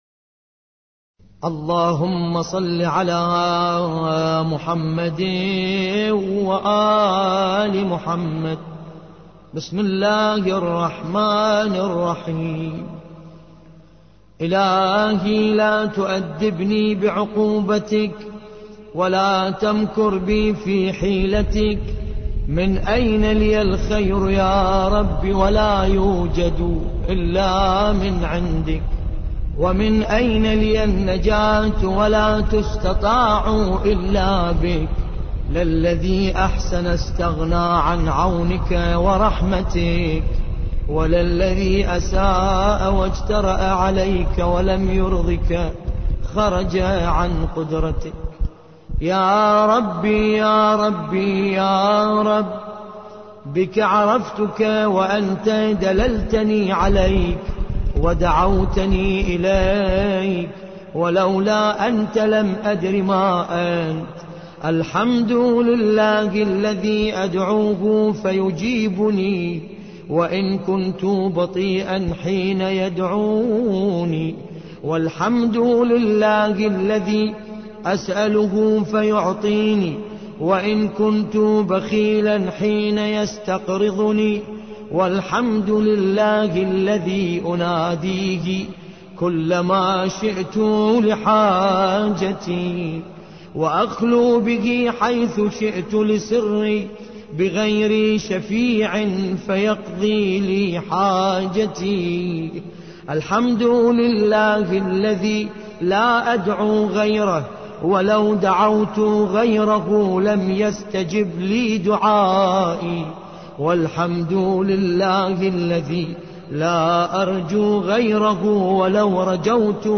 ملف صوتی دعاء ابي حمزه الثمالي بصوت باسم الكربلائي
الرادود : باسم الکربلائی